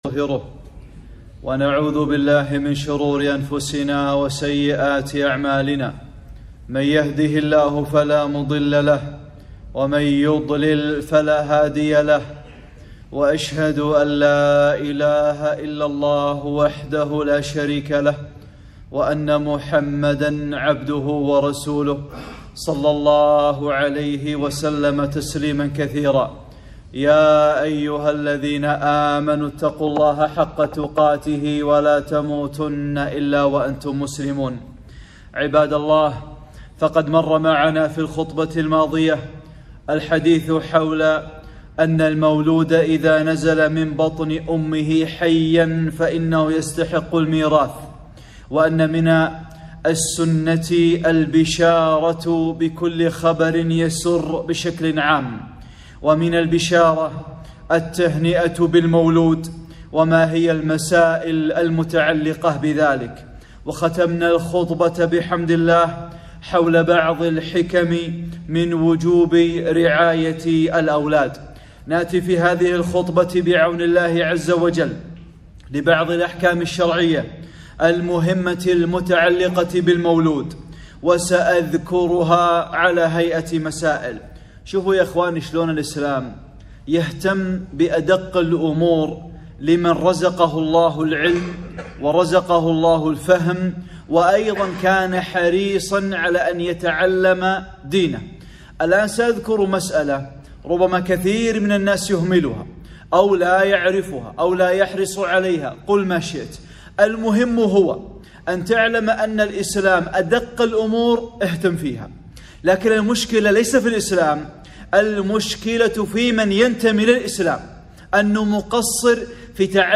(12) خطبة - الأذان والإقامة في أذن المولود - أمور هامة متعلقة بالآباء والأمهات